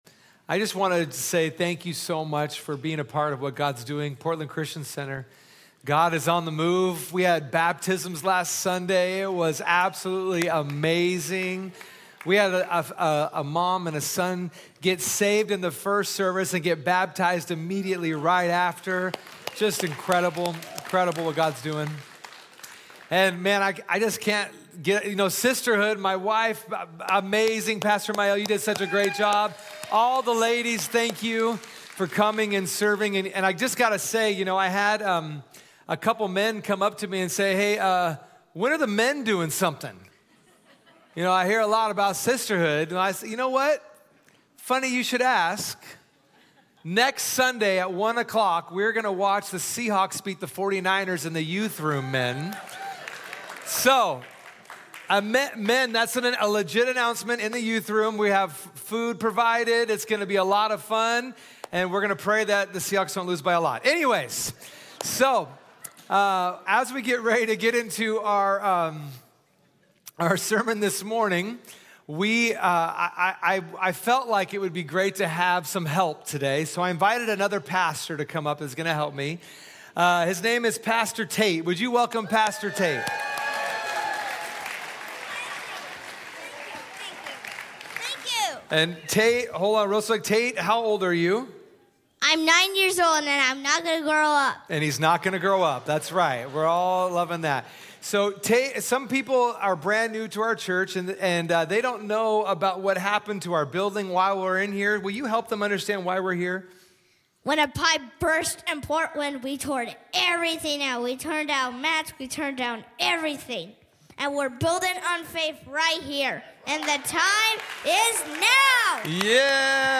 Sunday Messages from Portland Christian Center The Treasure Principle, Part 5 Nov 10 2024 | 00:44:12 1x 00:00 / 00:44:12 Subscribe Share Spotify RSS Feed Share Link Embed